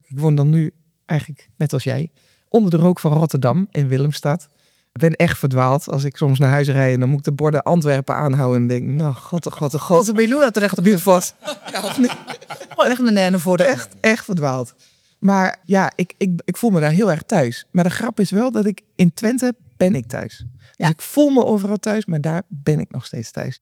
Een intiem en eerlijk gesprek, op een onverwachte plek.